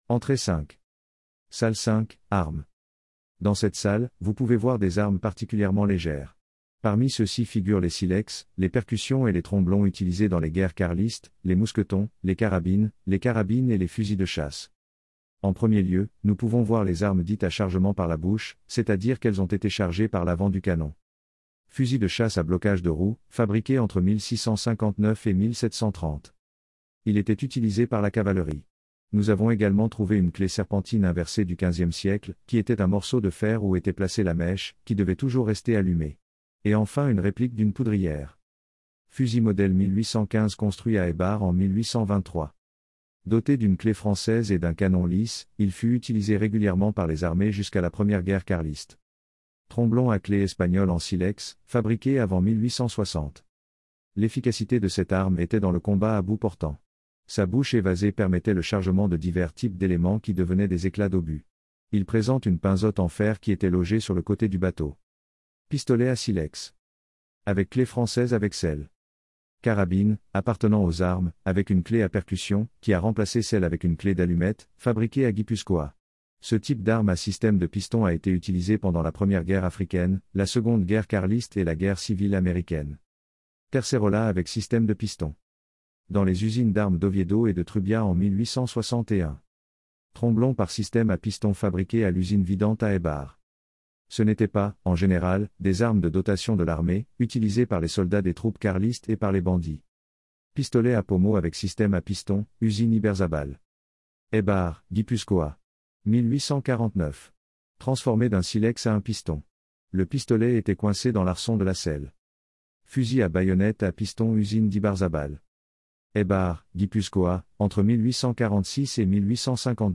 Audioguías: